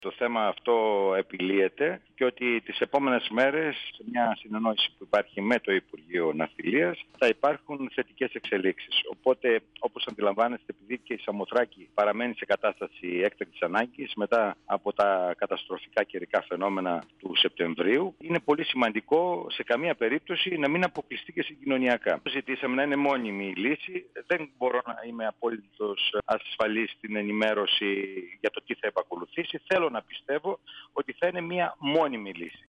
Ο αντιπεριφερειάρχης Εβρου, Δημήτρης Πέτροβιτς, στον 102FM του Ρ.Σ.Μ. της ΕΡΤ3
Συνέντευξη